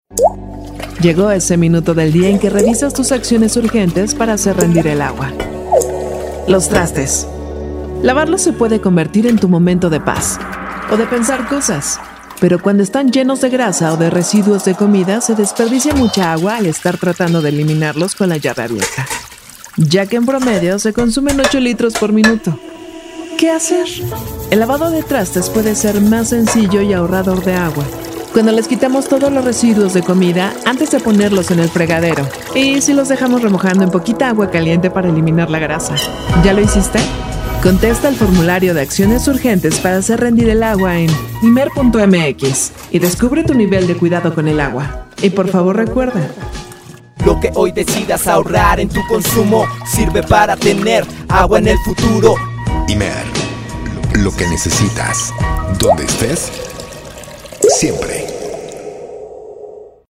ACTUACIÓN DRAMÁTICA